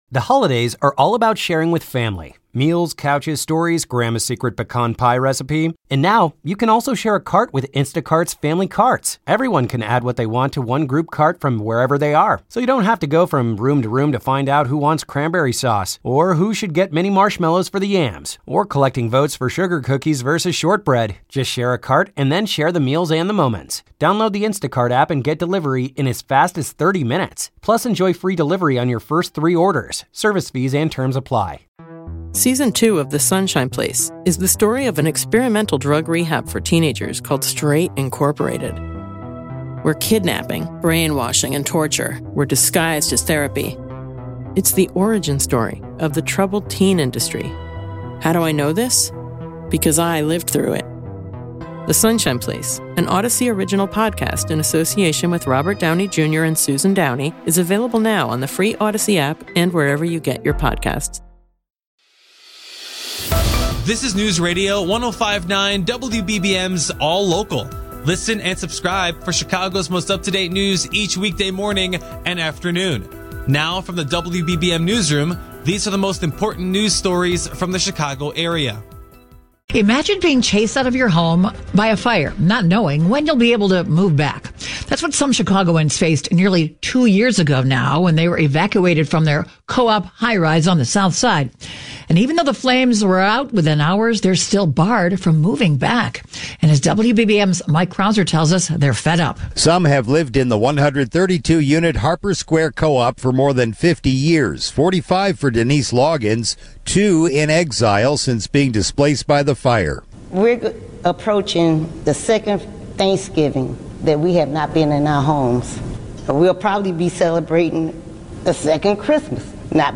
A dive into the top headlines in Chicago, delivering the news you need in 10 minutes or less multiple times a day from WBBM Newsradio.